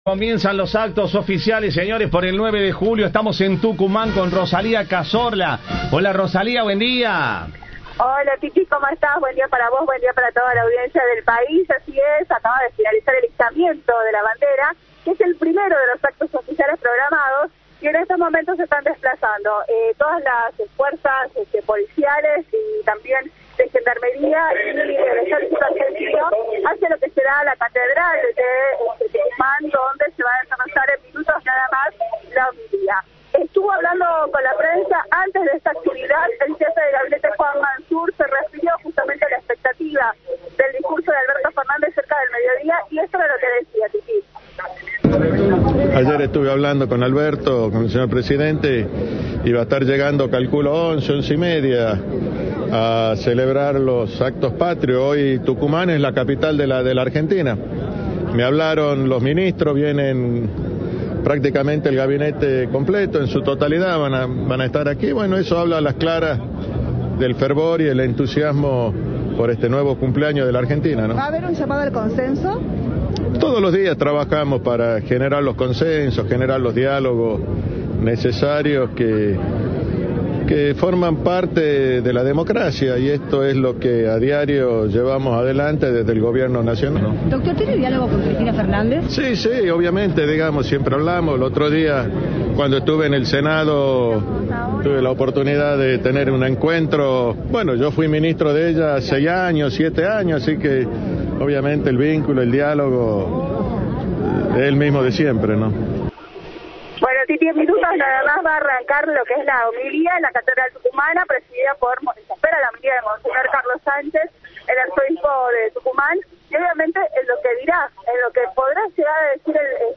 "Hoy tenemos que unirnos y generar estos vínculos de confianza para sacar a la Argentina definitivamente adelante. La celebración de la independencia significa redoblar la esperanza para un futuro mejor, una Argentina que siempre buscamos que incluya, que genere oportunidades, que mire hacia adelante", dijo Manzur en declaraciones a la prensa en la capital tucumana, tras la ceremonia de izamiento de la bandera nacional en el mástil de la Plaza Independencia.
Informe